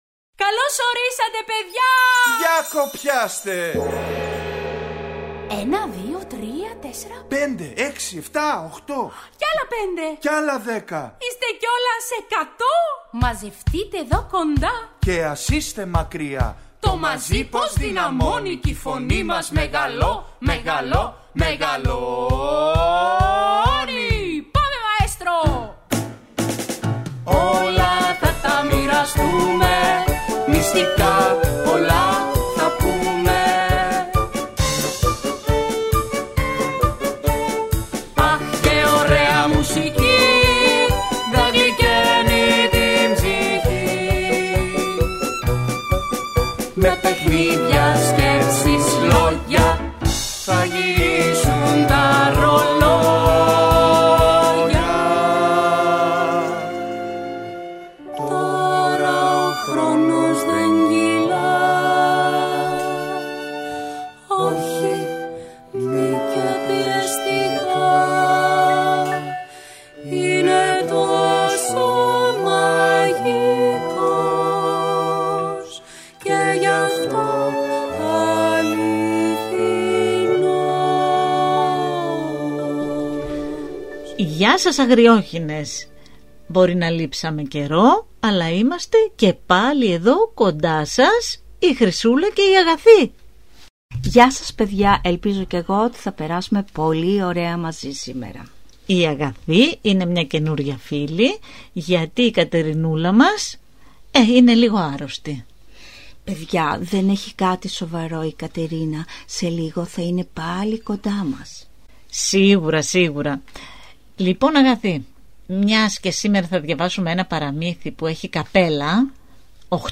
Ακούστε στην παιδική εκπομπή ‘’Οι Αγριόχηνες’’ το παραμύθι «Οχτώ μικρά καπέλα» της Μπέκυ Μπλουμ.